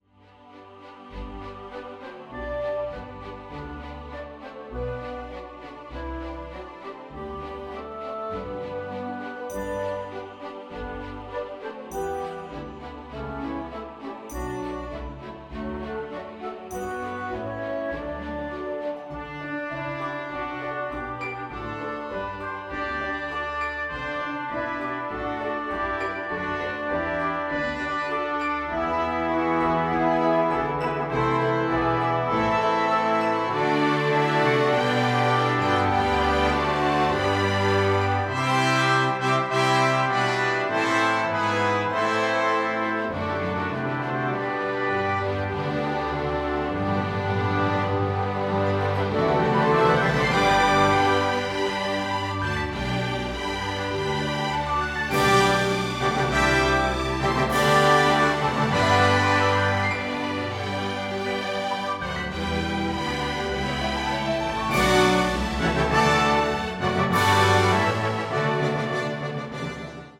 Choir
2 Flutes
Oboe
2 Clarinets
Bassoon
3 Horns
3 Trumpets
2 Trombones
Tuba
Timpani
Percussion (Cymbals, Triangle)
Harp
Piano
Organ (Opt.)
Cello
Double Bass